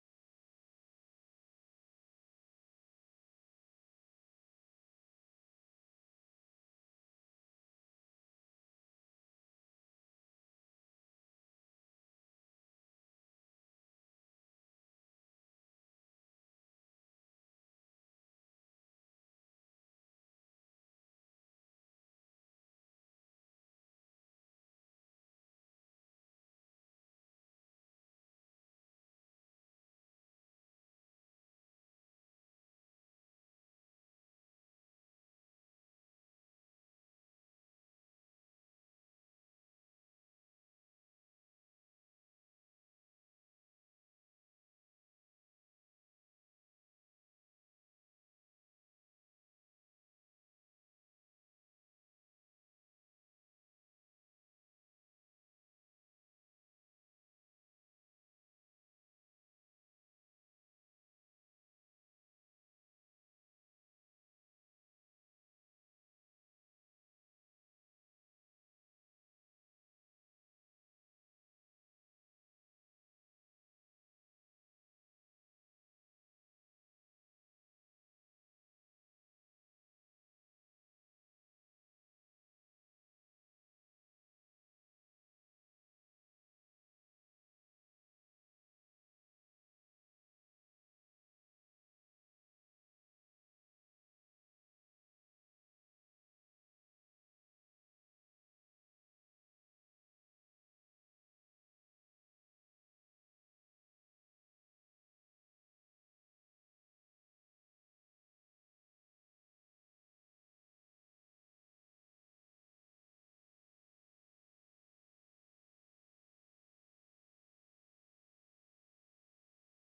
Deze animatievideo van de Koninklijke Marechaussee vertelt welke (extra) KMar-inzet er nodig is voor de aanstaande NAVO-top in Den Haag. De tekst van de voice-over wordt ondersteund door animaties.